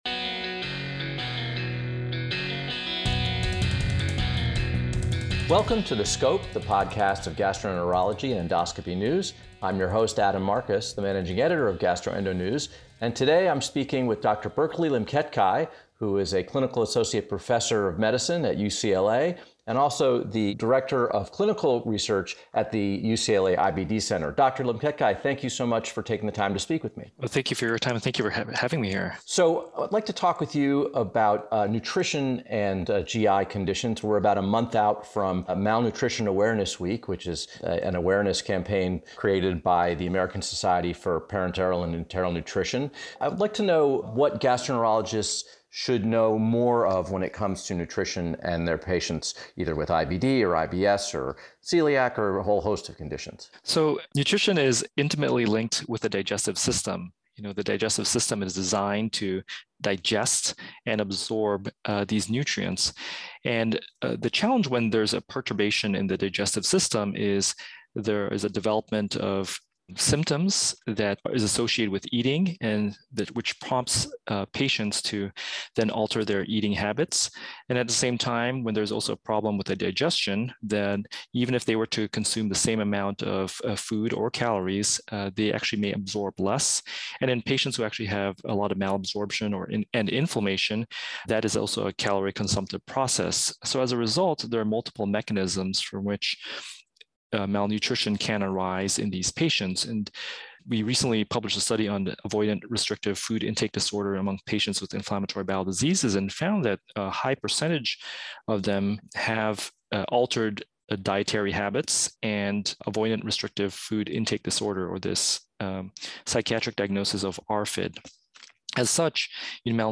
Note: This interview was recorded in early September.